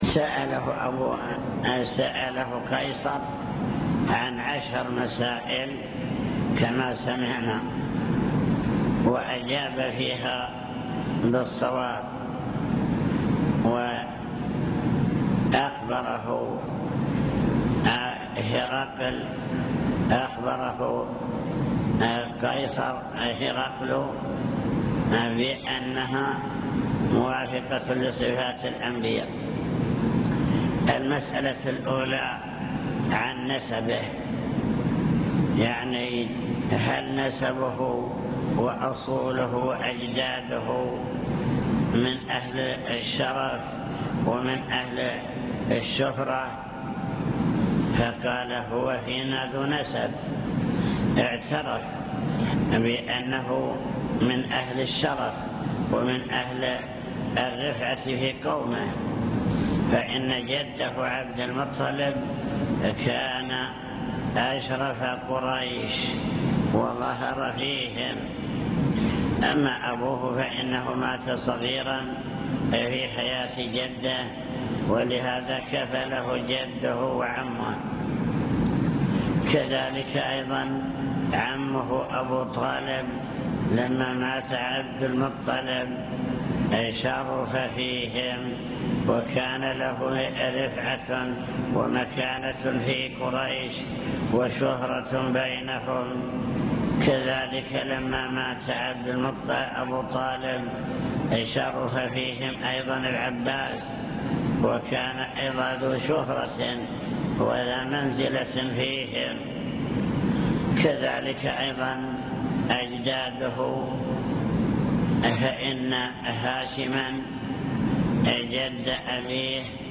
المكتبة الصوتية  تسجيلات - كتب  باب بدء الوحي من صحيح البخاري شرح حديث أبي سفيان مع هرقل